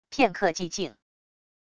片刻寂静wav音频